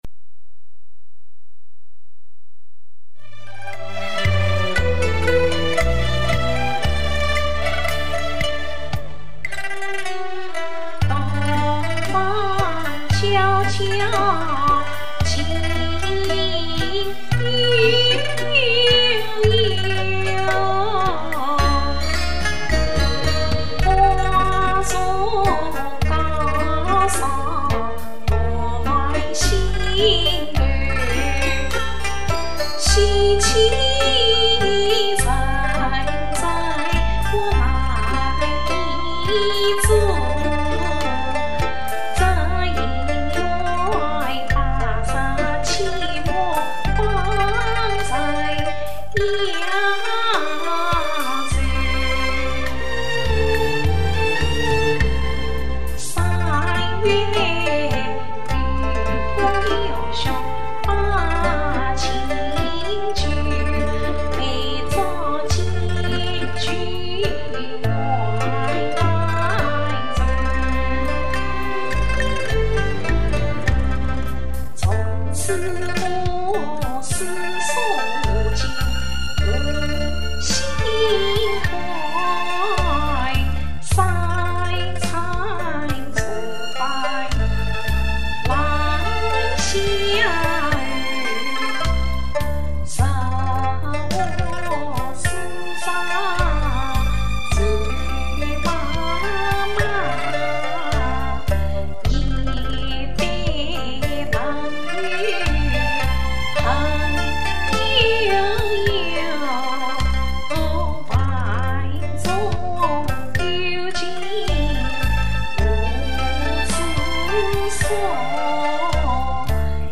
学唱越剧《盘妻索妻》-- 洞房悄悄静幽幽 - 博客 | 文学城